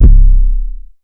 archived music/fl studio/drumkits/slayerx drumkit/808s